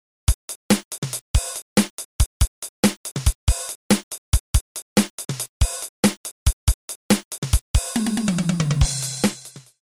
Five fingers in a hand, five beats to a bar, two hands, two bars, one inside-out groove. Play this with a click to see how far out your fills can go without breaking the groove.